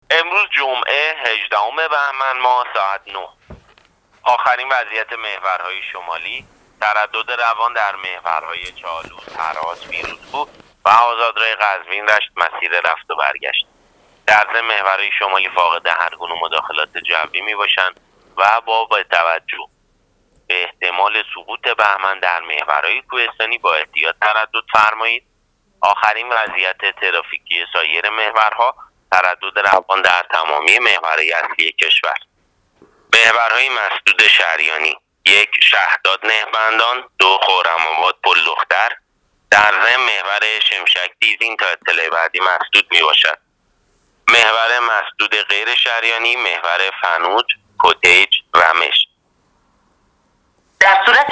گزارش رادیو اینترنتی از آخرین وضعیت ترافیکی جاده‌ها تا ساعت۹ جمعه ۱۸ بهمن‌ماه۱۳۹۸